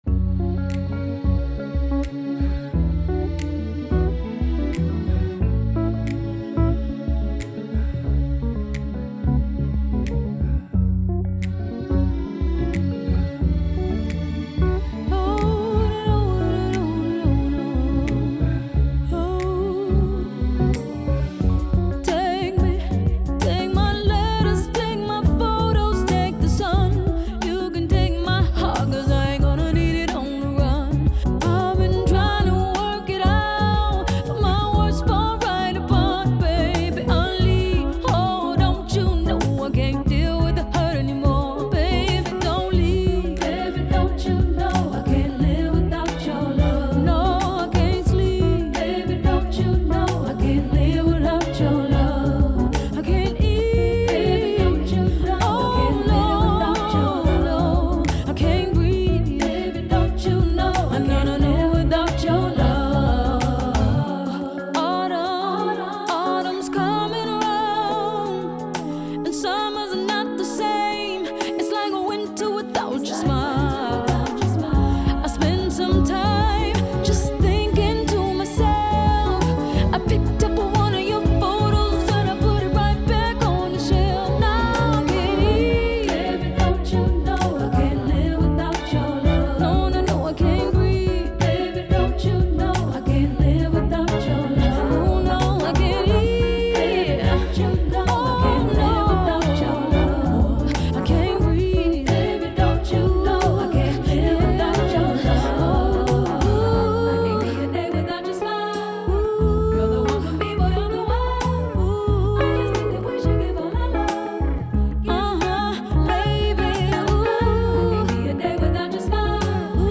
Bound By Books - Youth Radio: Bound By Books - February 14, 2025 (Audio)